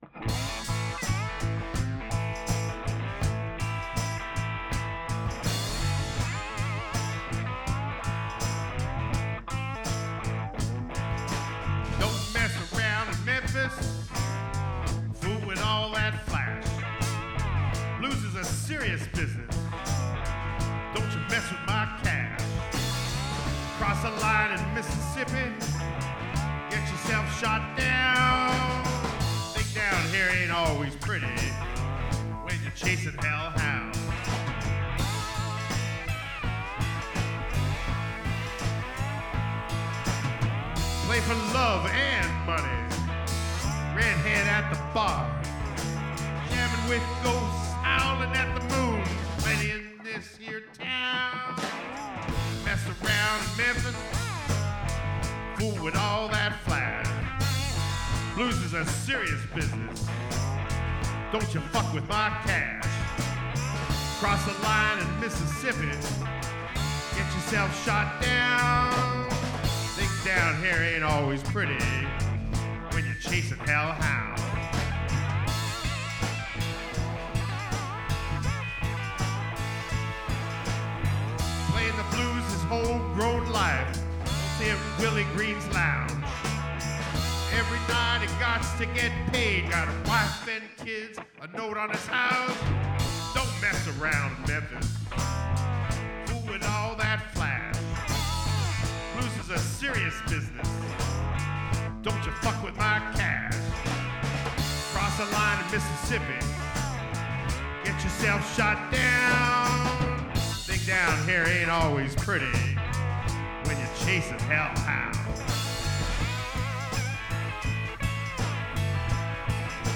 live off the floor
It certainly has that vintage sound.
I added bass independently and did the preliminary mixing.
which captured the fun and energy of the performances.